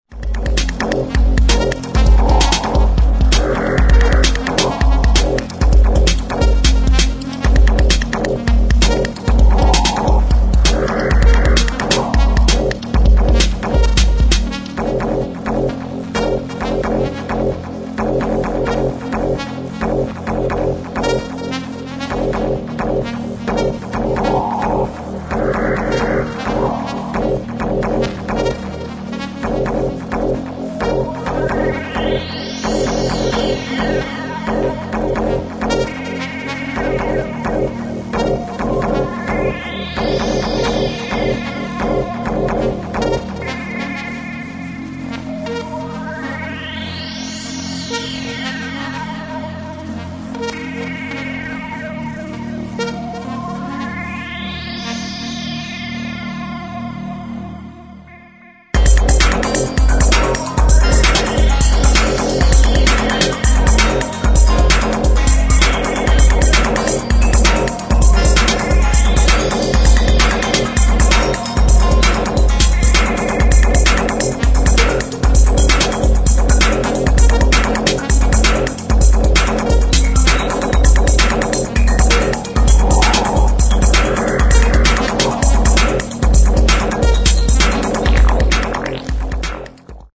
[ ELECTRO ]